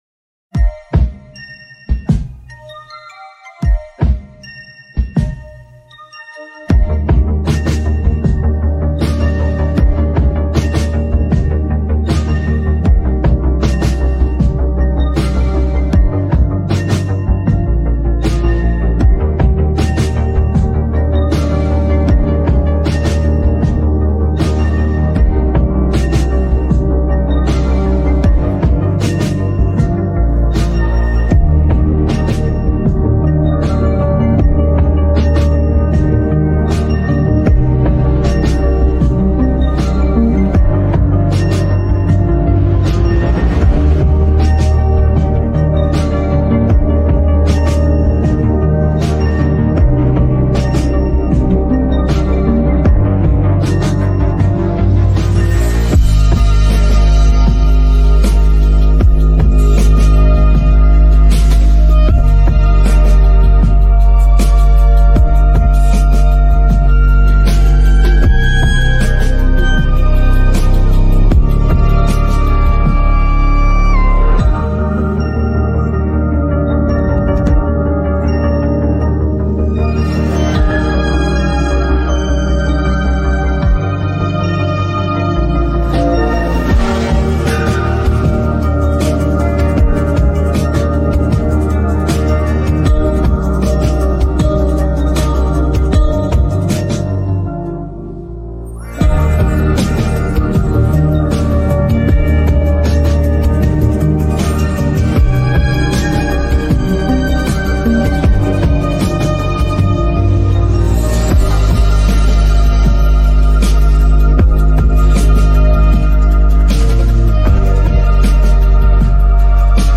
[instrumental]🥂🌅✨ (this song is so dreamy and girlie